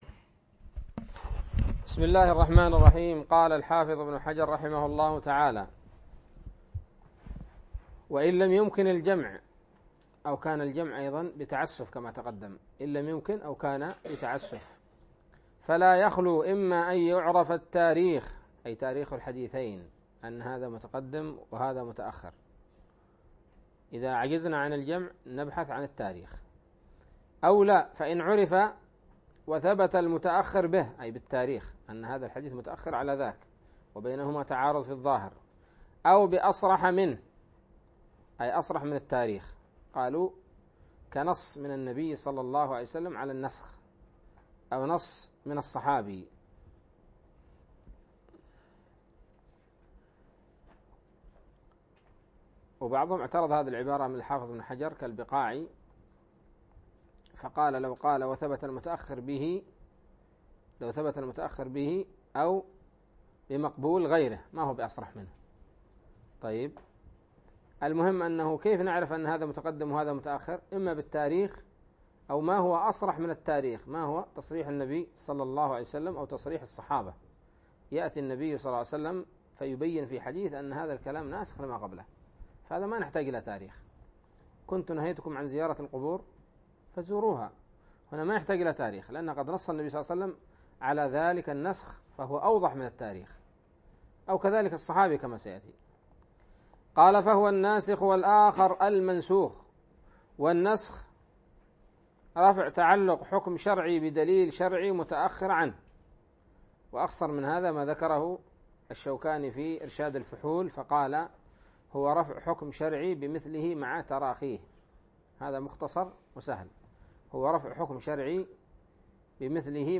الدرس السابع عشر من شرح نزهة النظر